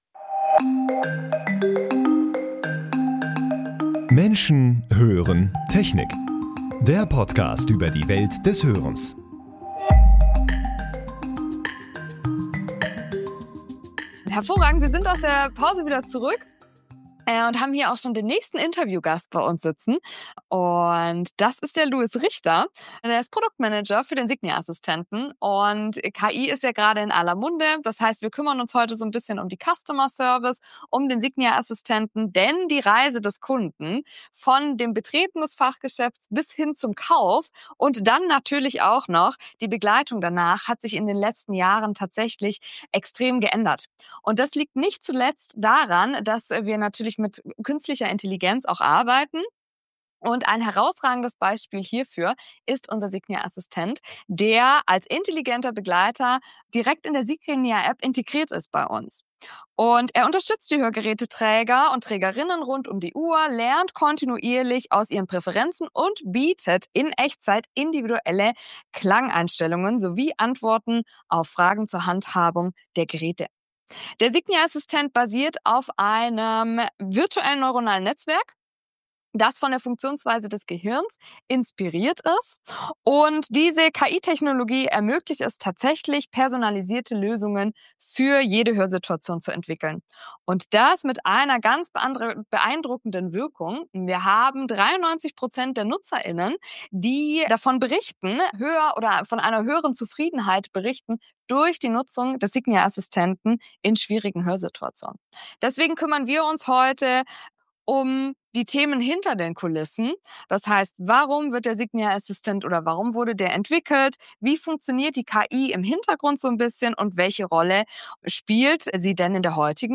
Dies ist Episode 2 der Interview-Reihe, die mit Entwicklerinnen und Entwicklern zum Thema Hörgeräte, digitale Services und Designs geführt wurde.